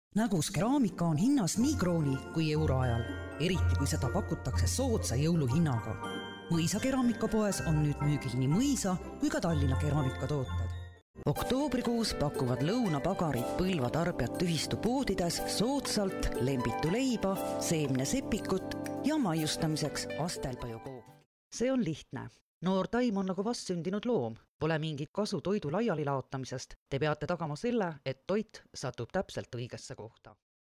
Weiblich